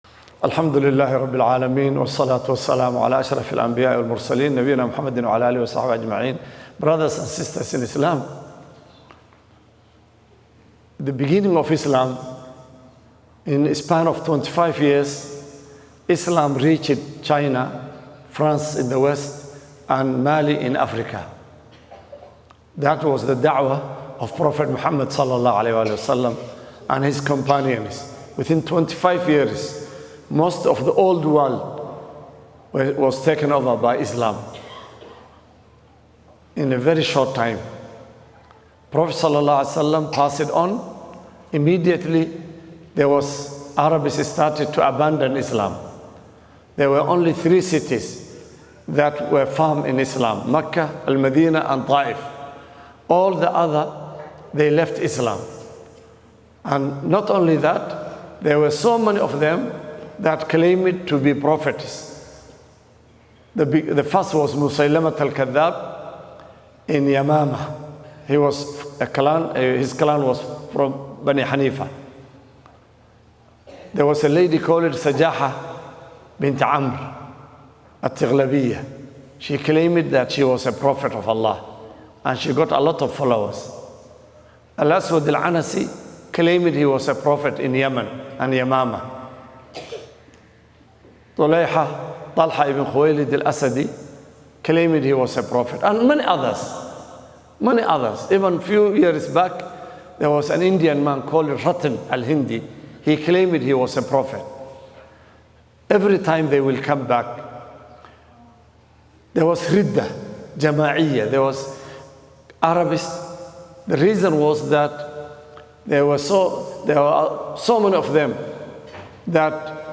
Khutbah